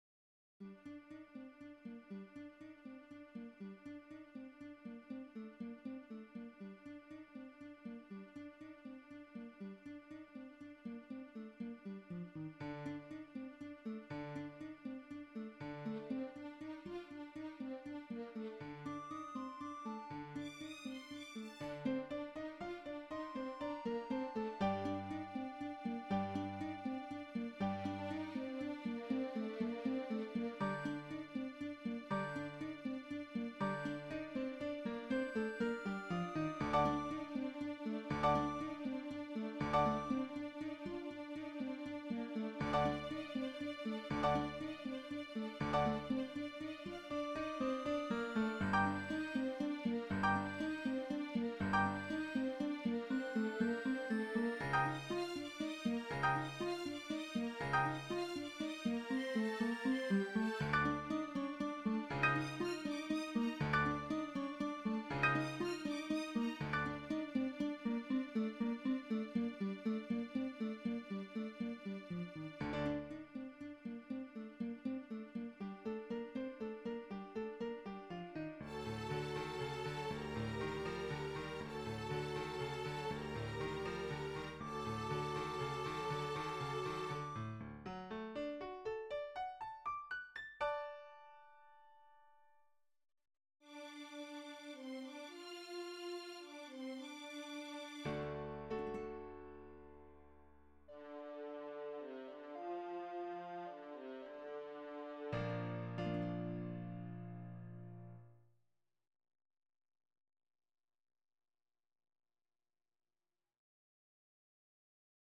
*     Альбениц. Астурия(Leyenda) из Испанской Сюиты (для гитары, блок-флейты, струнных и ф-но)
(VSTiшное исполнение)